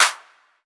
Clap
Original creative-commons licensed sounds for DJ's and music producers, recorded with high quality studio microphones.
Clap Sample F# Key 13.wav
clap-sound-clip-f-sharp-key-17-x7O.wav